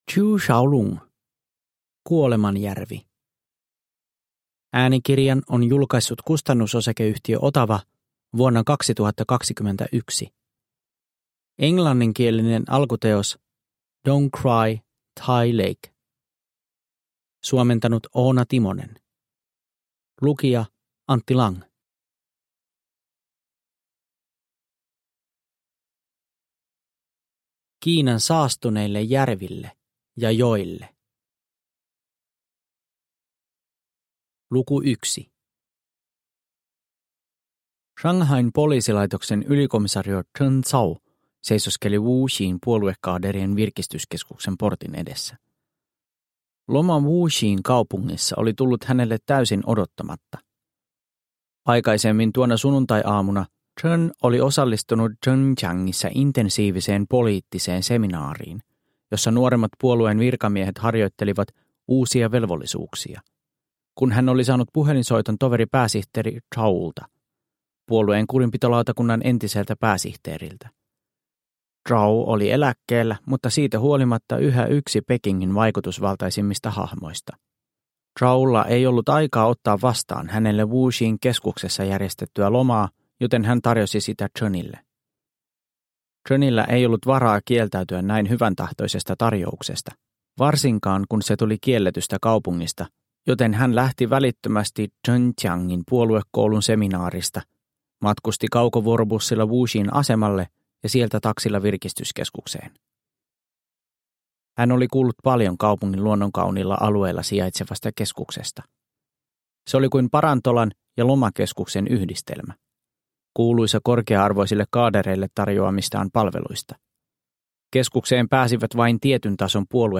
Kuolemanjärvi – Ljudbok – Laddas ner